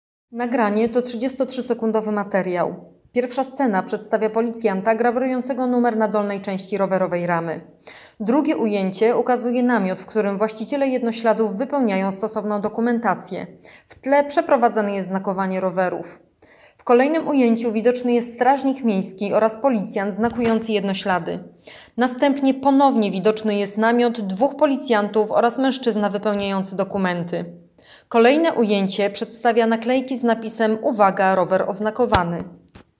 Nagranie audio Audiodeskrypcja filmu.m4a